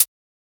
Closed Hats
edm-hihat-04.wav